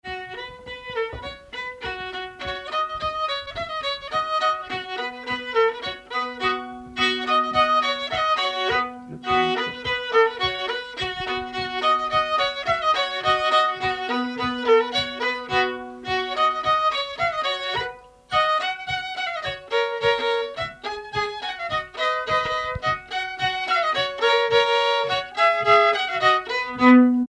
Polka
Saint-Nicolas-la-Chapelle
danse : polka
circonstance : bal, dancerie
Pièce musicale inédite